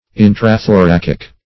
Intrathoracic \In`tra*tho*rac"ic\, a. Within the thorax or chest.